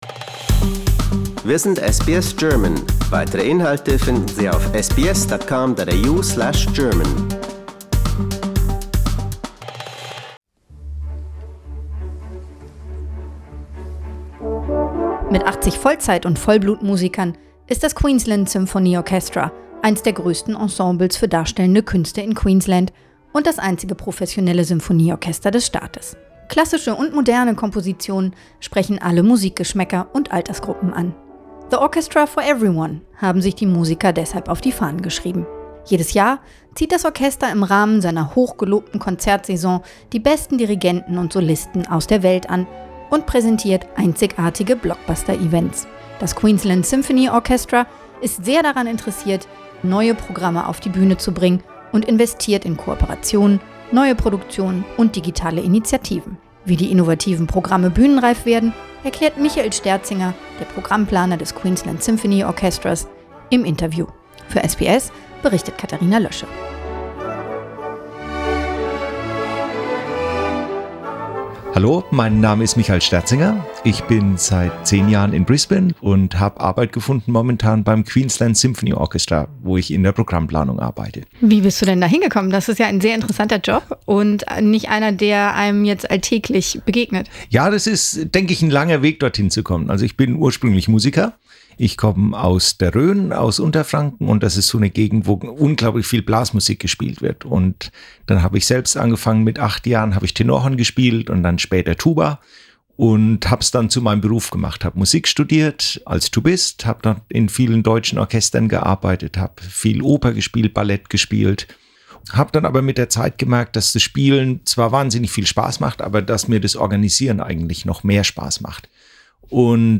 interview
im Studio